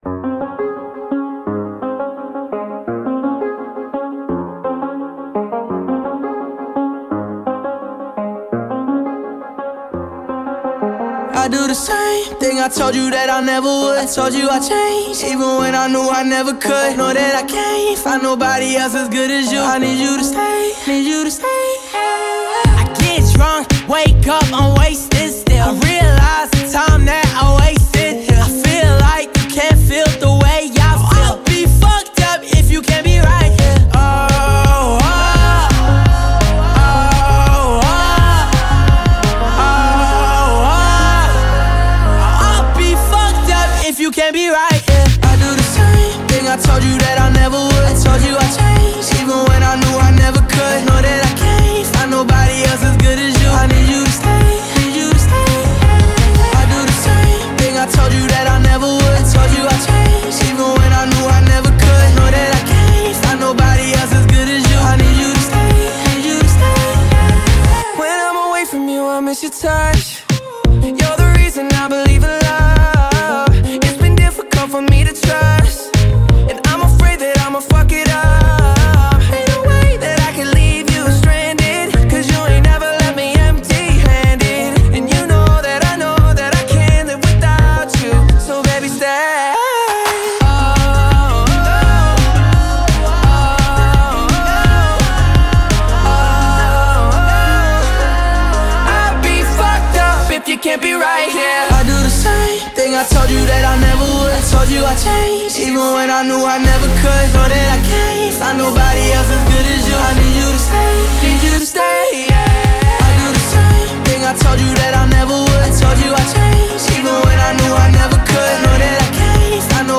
BPM170